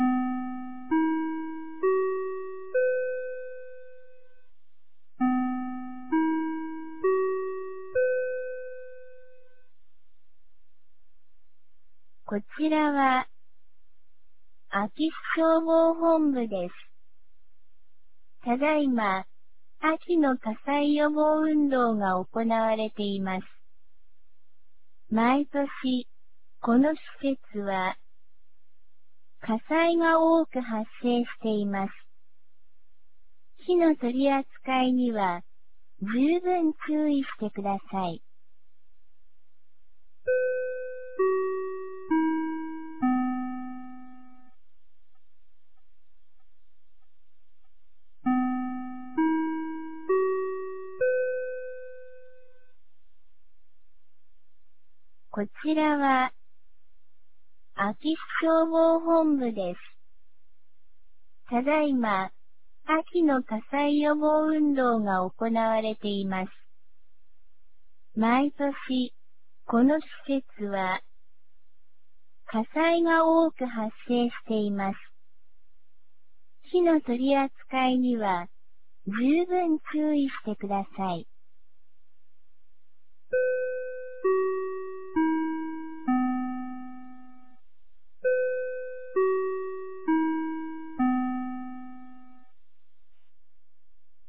2023年11月10日 16時01分に、安芸市より全地区へ放送がありました。